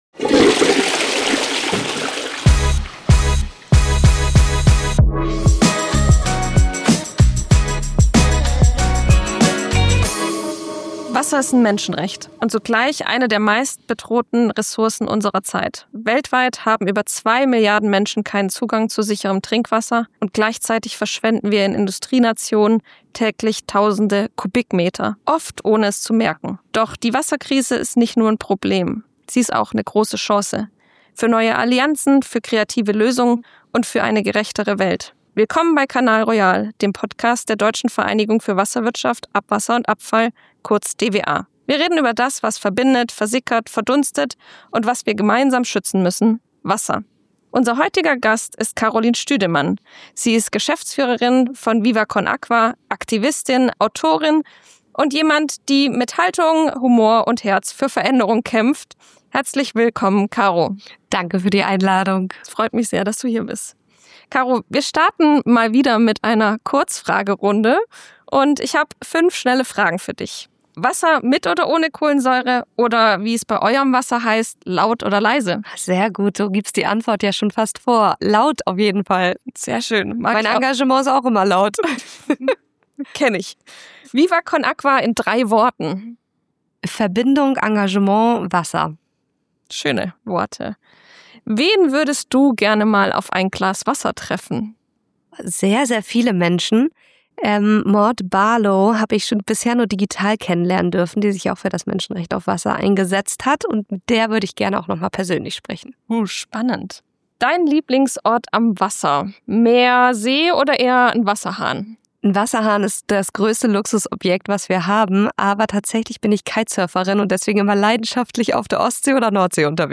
Ein Gespräch über globale Verantwortung, lokale Lösungen und die Kraft von Engagement.